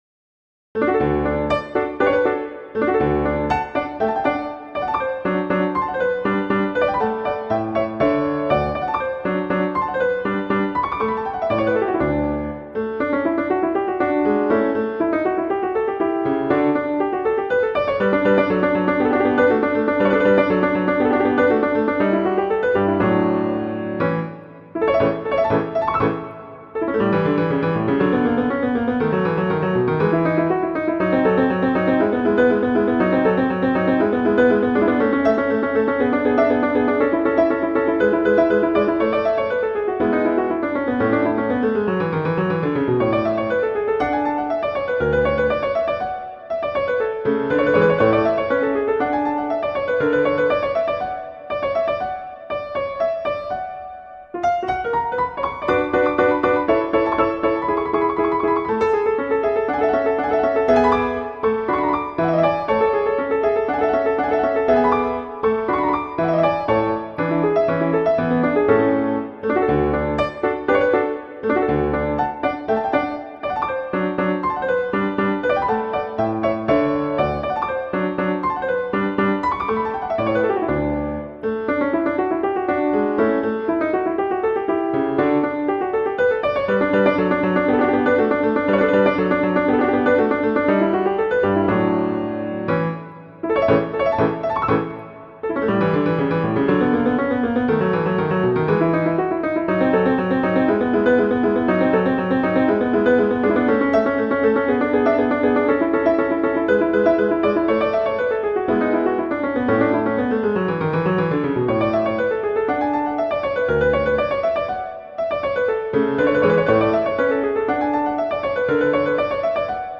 piano solo
classical
Allegro